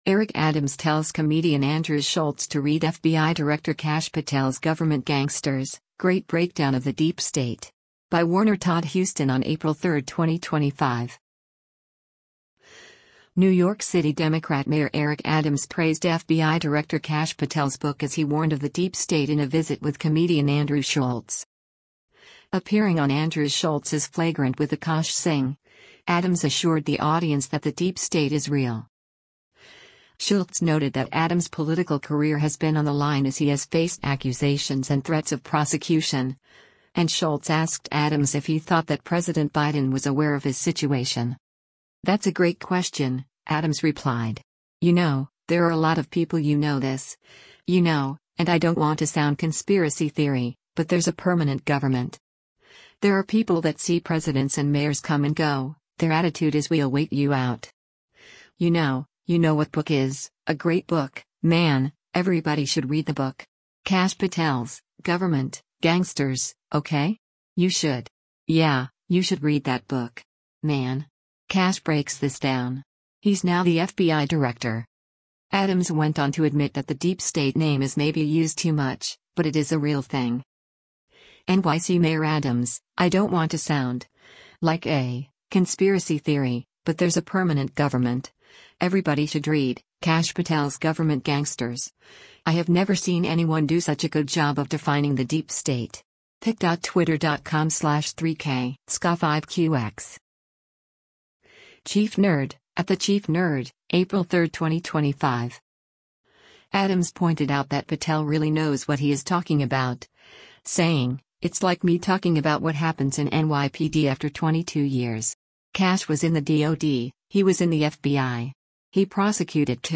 Appearing on Andrew Schulz’s Flagrant with Akaash Singh, Adams assured the audience that the deep state is real.